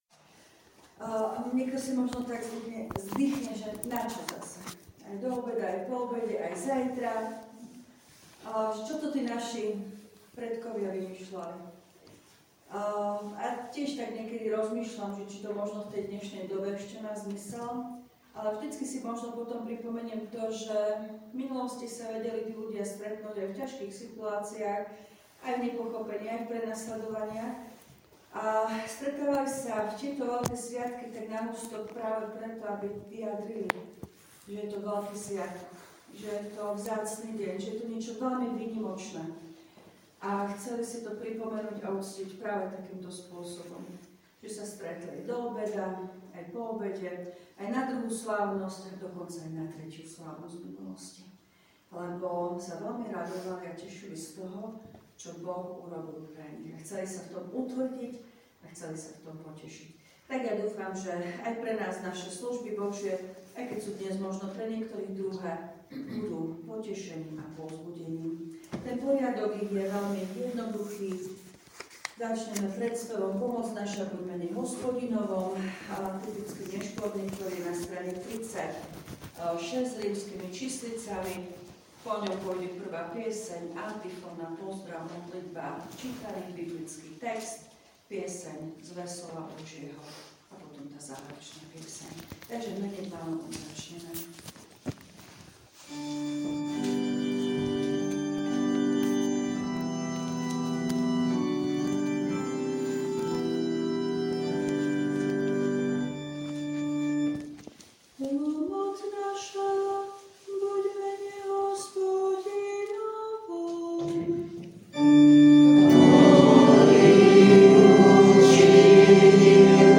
V nasledovnom článku si môžete vypočuť zvukový záznam z Nešporných služieb Božích na Veľkonočnú nedeľu.